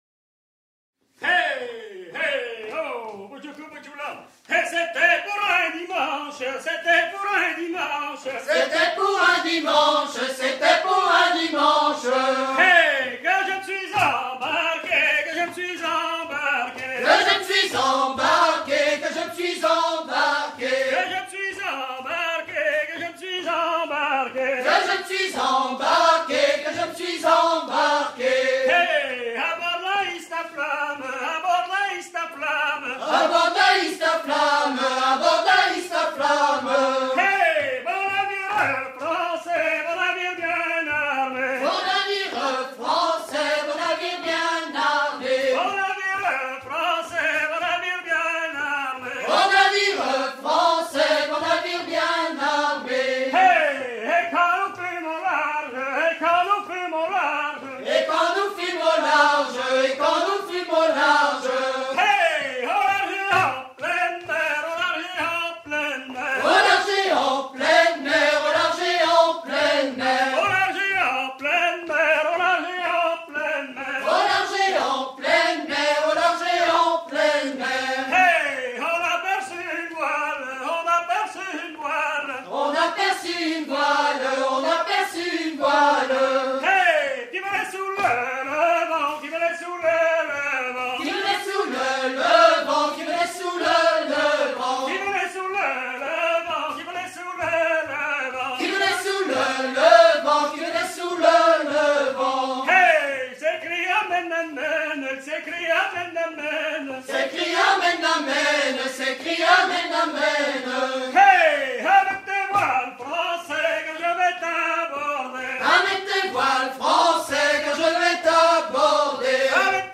Menée en paulée du Marais Breton-Vendéen
danse : ronde : grand'danse
Pièce musicale éditée